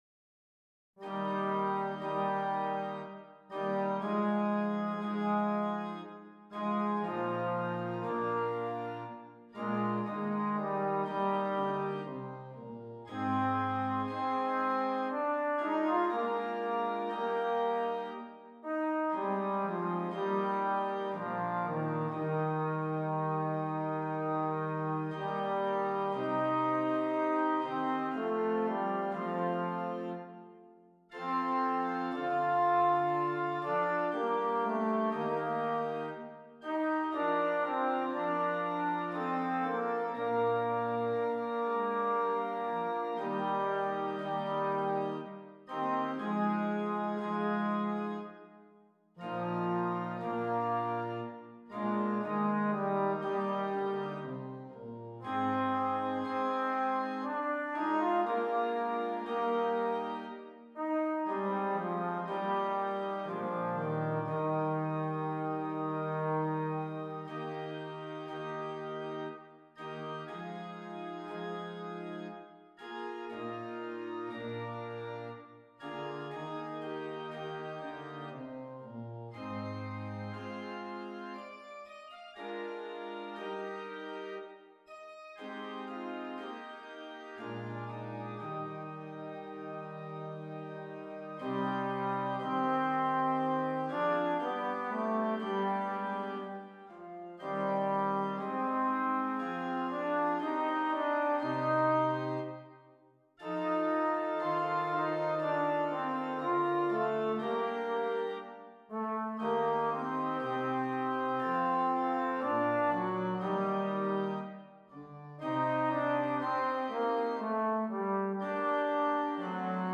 Trombone and Keyboard